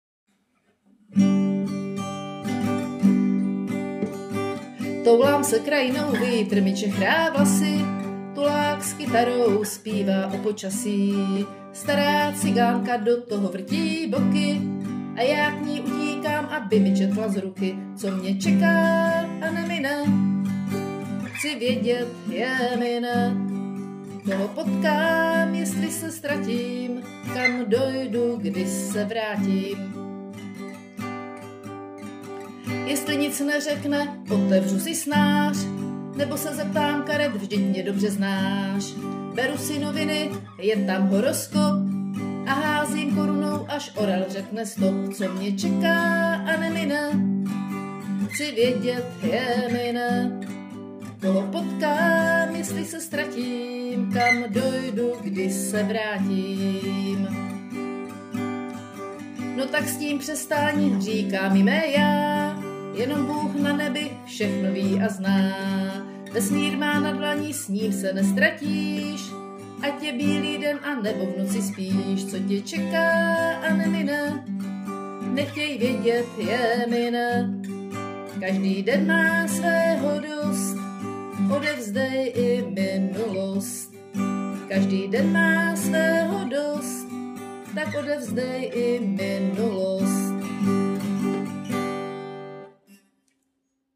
hravá, rytmická a moudrá báseň/píseň
Má to v sobě takovou tu poctivou písničkářskou člověčinu.